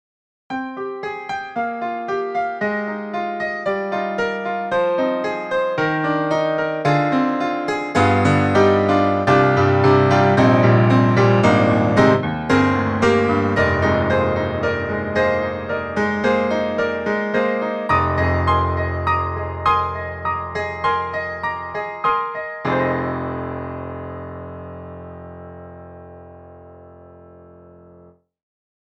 特集：徹底比較！DTM音源ピアノ音色聴き比べ - S-studio2
4Front_Truepianos_Sapphire_Pop.mp3